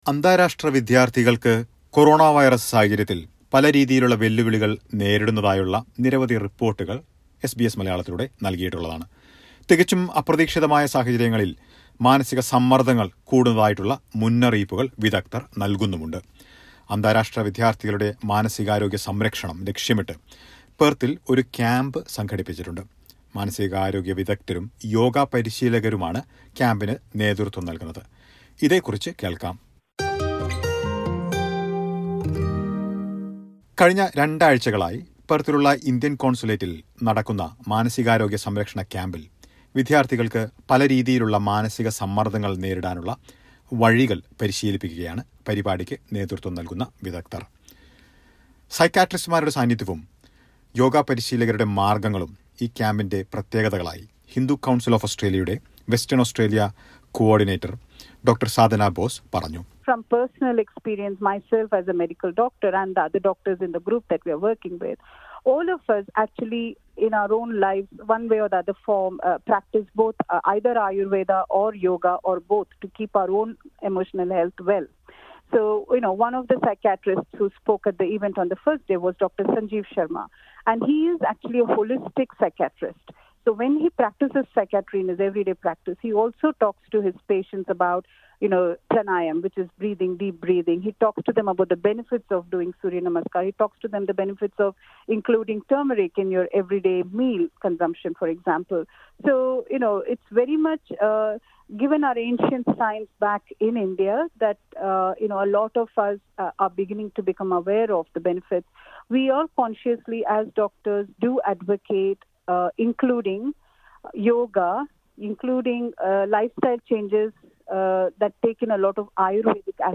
A well-being camp was conducted in Perth for international students aimed at creating awareness about handling the pressures due to the pandemic. The event conducted jointly by the Indian Consulate in Perth and the Hindu Council of Australia had mental health experts and yoga trainers providing support to students. Listen to a report.